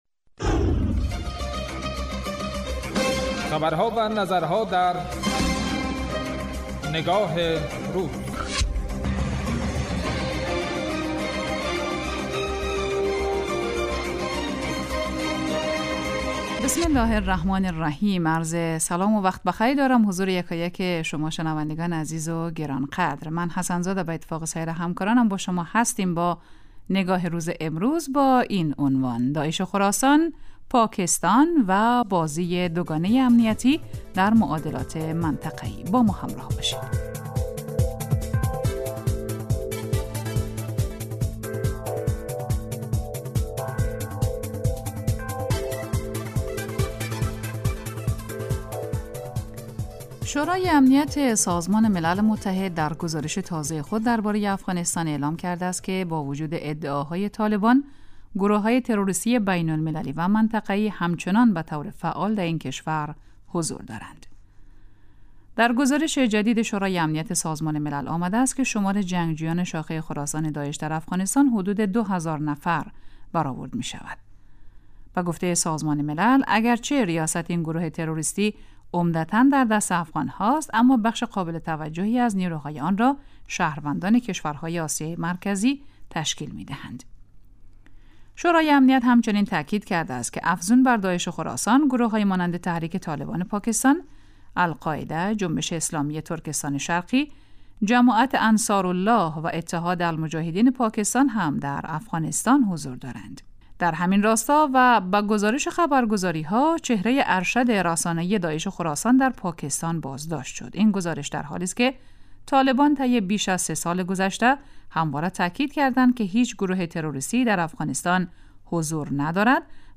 اطلاع رسانی و تحلیل و تبیین رویدادها و مناسبت‌های مهم، رویکرد اصلی برنامه نگاه روز است که روزهای شنبه تا پنج‌شنبه ساعت 13:00 به مدت 10 دقیقه پخش می‌شود.